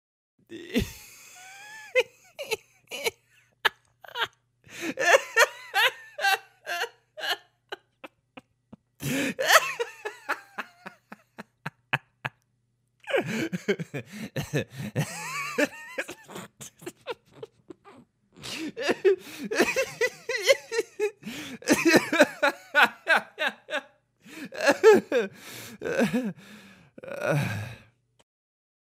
دانلود صدای خندیدن مرد 1 از ساعد نیوز با لینک مستقیم و کیفیت بالا
جلوه های صوتی
برچسب: دانلود آهنگ های افکت صوتی انسان و موجودات زنده دانلود آلبوم صدای خندیدن مرد از افکت صوتی انسان و موجودات زنده